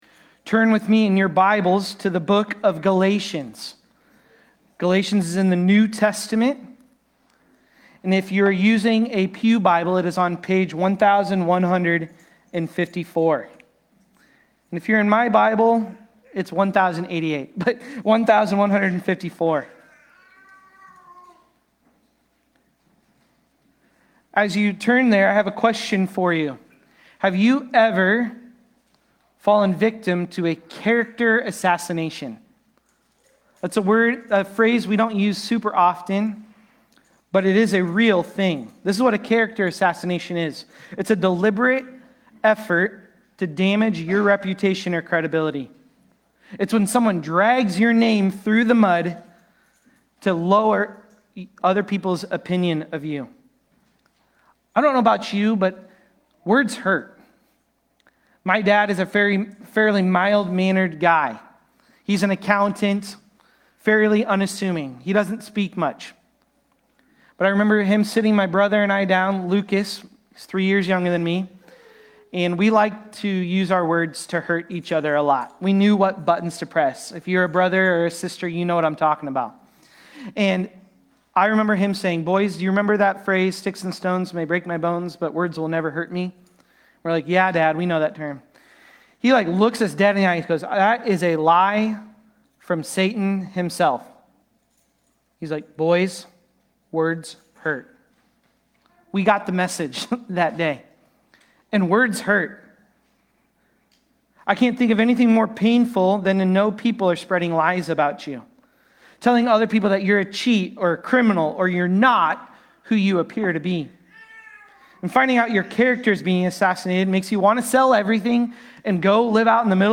Galatians-1.11-24-Sermon-Audio.mp3